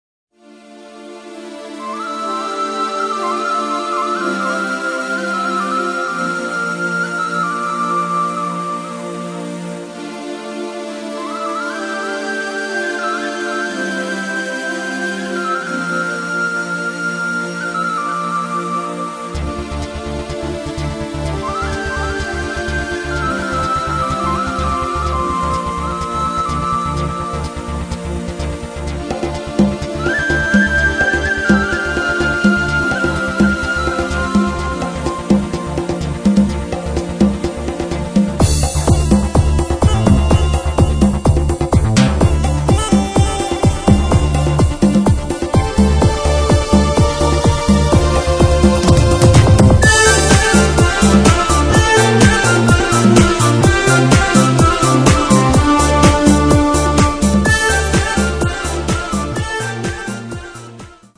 saxofoon, klarinet, gitaar en piano
tribal rhythms and ethnic instruments